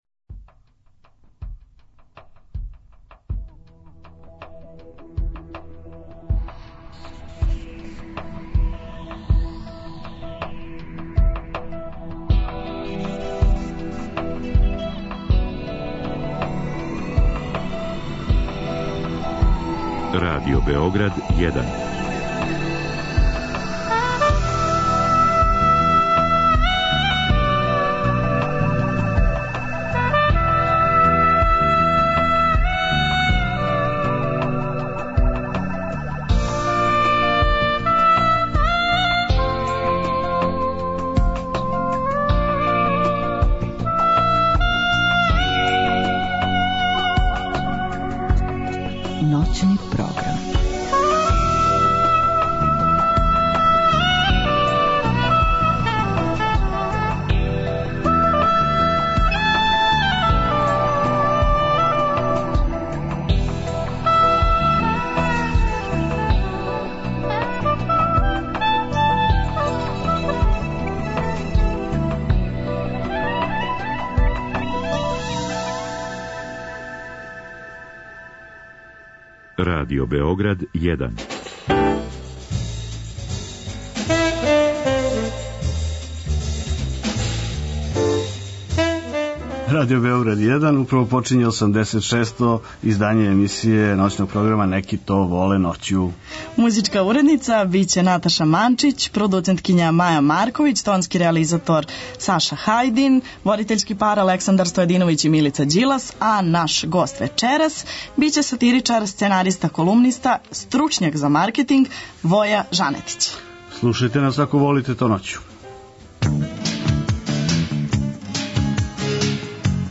преузми : 56.19 MB Ноћни програм Autor: Група аутора Сваке ноћи, од поноћи до четири ујутру, са слушаоцима ће бити водитељи и гости у студију, а из ноћи у ноћ разликоваће се и концепт програма, тако да ће слушаоци моћи да изаберу ноћ која највише одговара њиховом укусу, било да желе да слушају оперу или их интересује технологија.